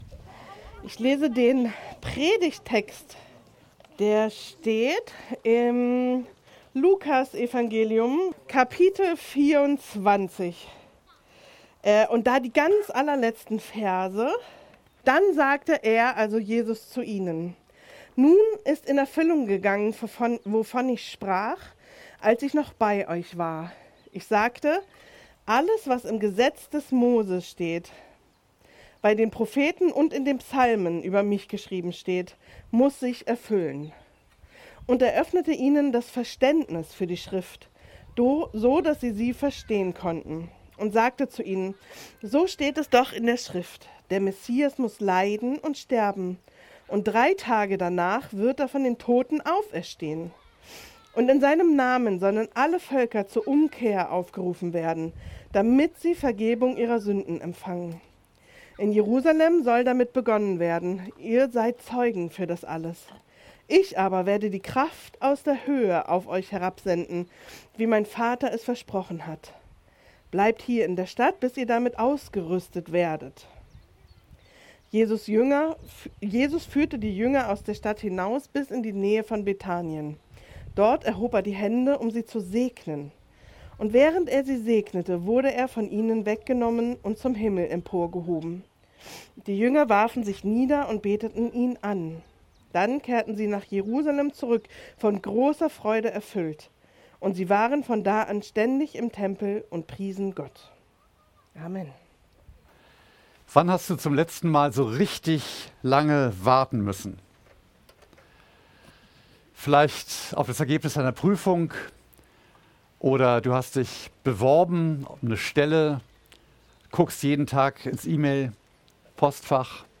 Eine predigt aus der serie "GreifBar+."